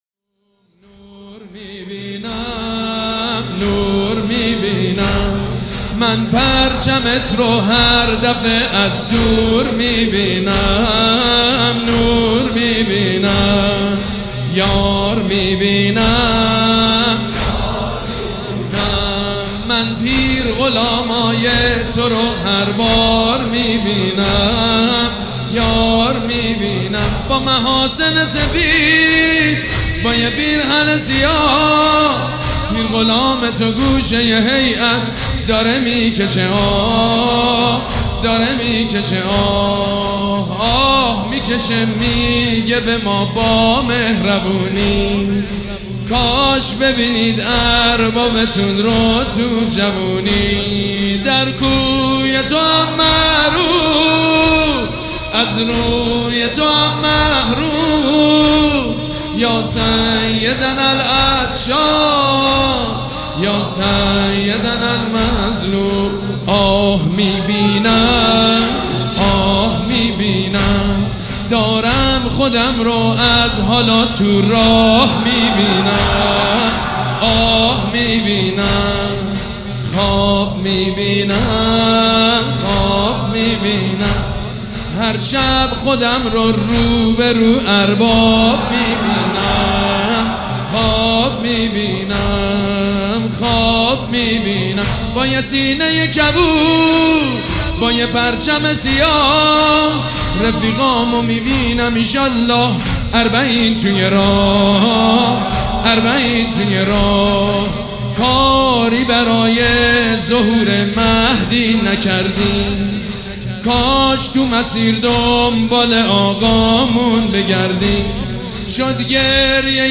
مداح
حاج سید مجید بنی فاطمه
مراسم عزاداری شب چهارم